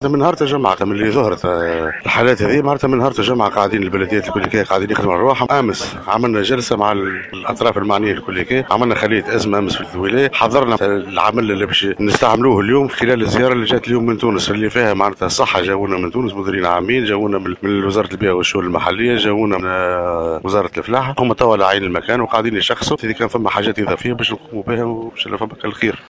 وتابع في تصريح لـ "الجوهرة أف أم" أنه تم احداث هذه الخلية بعد جلسة انعقدت أمس استعدادا لزيارة وفد من وزارات الصحة والبيئة والشؤون المحلية والفلاحة المتواجد حاليا على عين المكان في سوسة، وفق تعبيره، مؤكدا أن البلديات قامت بالاجراءات اللازمة منذ الاعلان عن ظهور المرض.